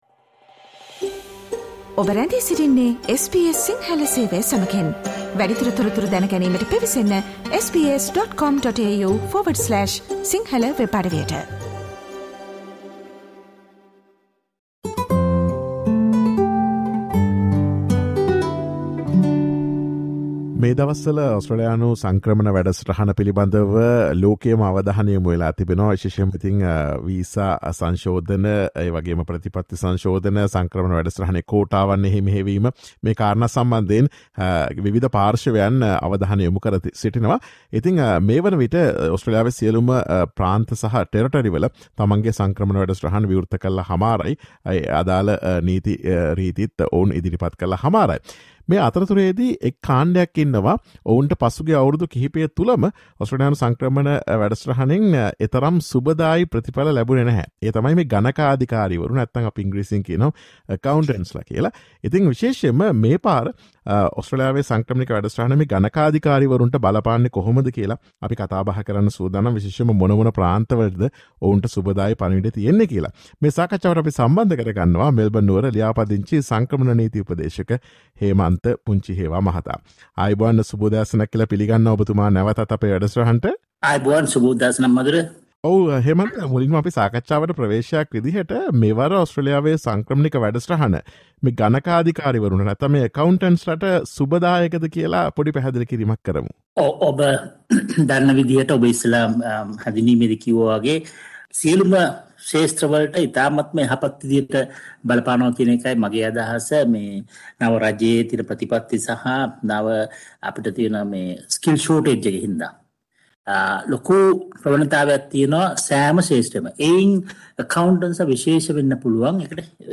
Listen to SBS Sinhala Radio's discussion on pathways for Accountants to gain permanent residency in Australia.